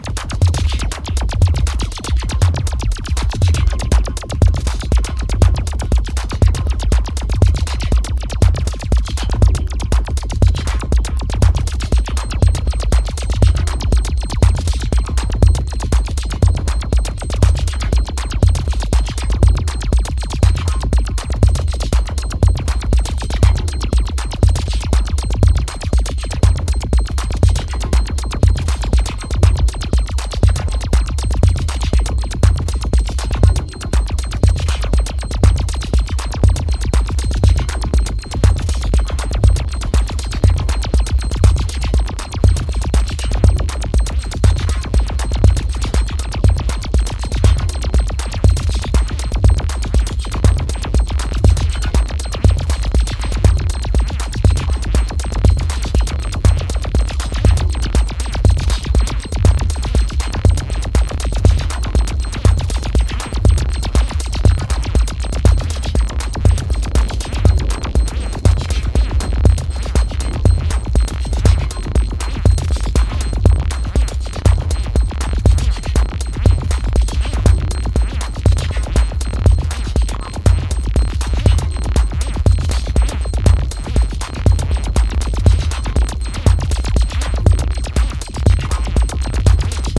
All tracks were recorded directly to tape with no overdubs